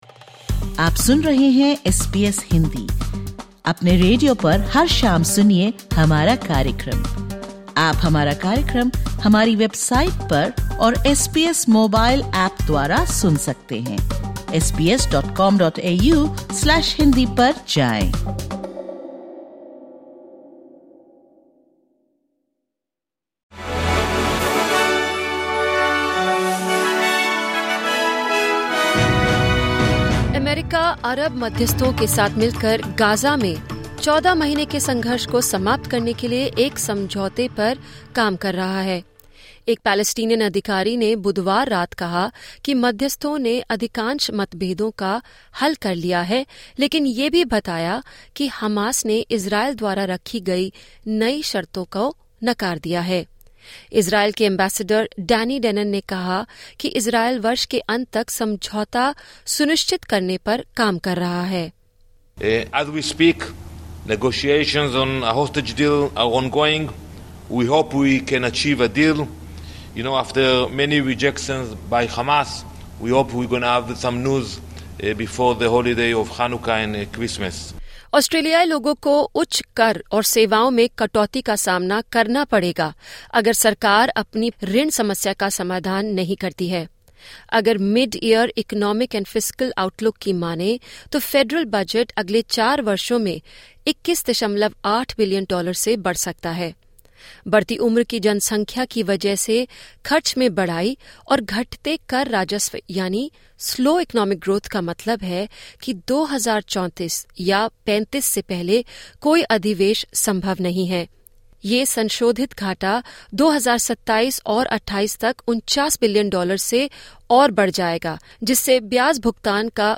Listen to the top News from Australia in Hindi.